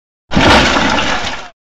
Car Crash Noise Sound Effect Free Download
Car Crash Noise